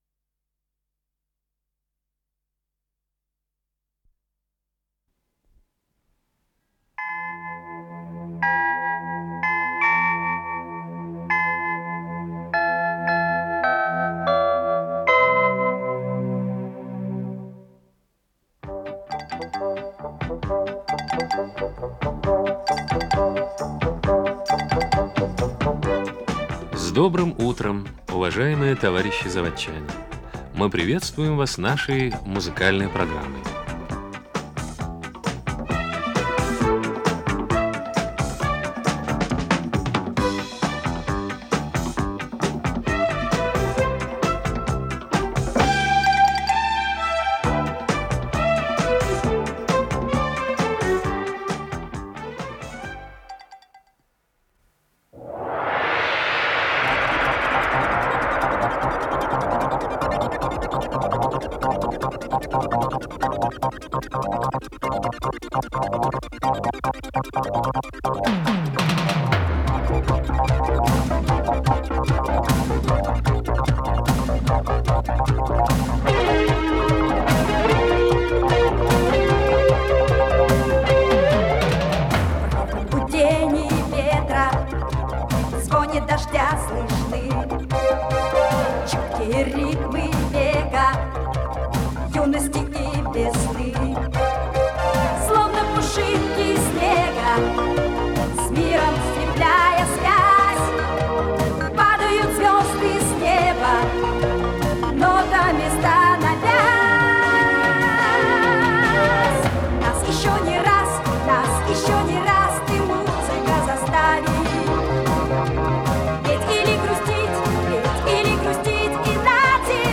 ИС-000 — Радиопередача